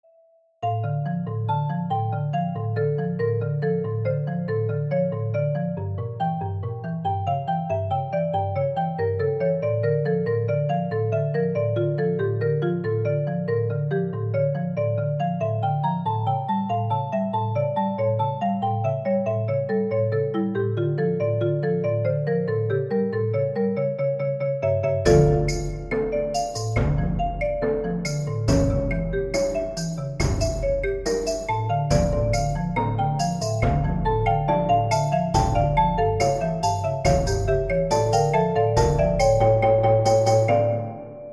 Every measure is a different time signature, in descending order, i.e. the first measure is in 8/8, the second in 7/8, etc. until it loops back from 1/8 to 8/8. If you're not familiar with music this essentially means it's hard to tap your foot along with it.